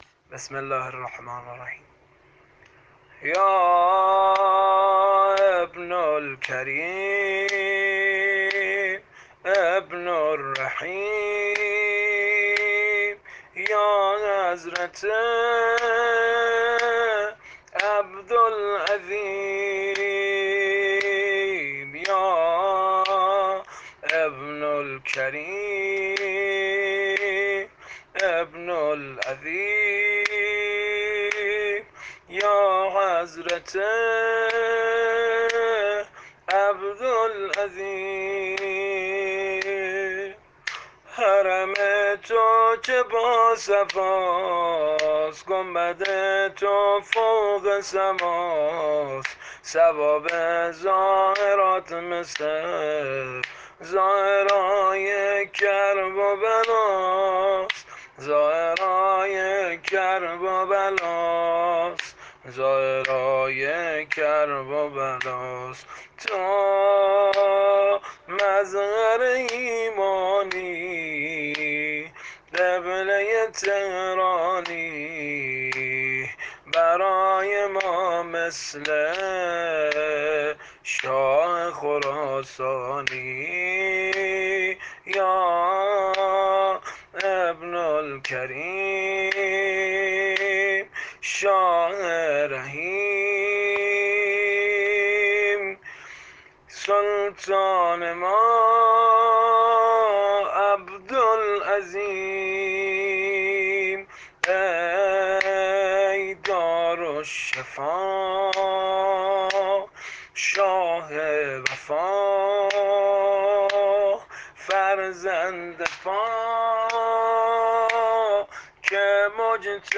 سبک زمینه، شور وفات حضرت عبدالعظیم(ع) -(یابن الکریم، ابن الرحیم...)